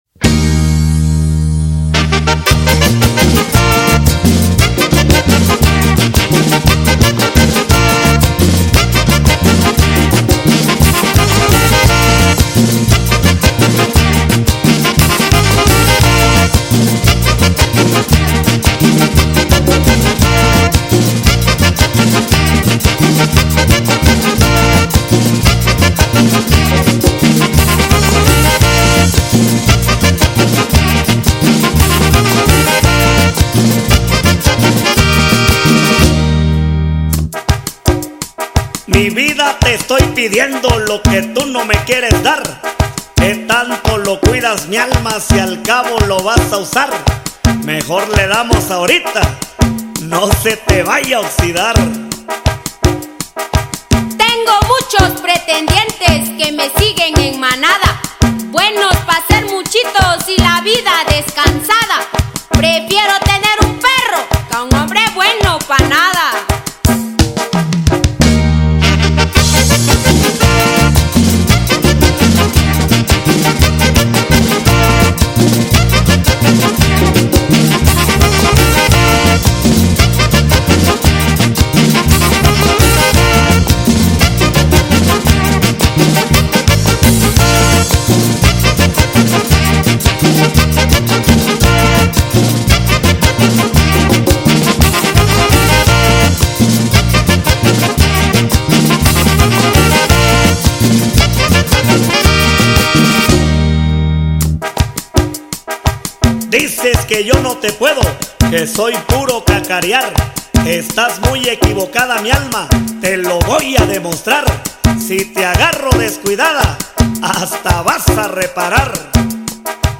Chilenas y sones istmeños
una chilena bailable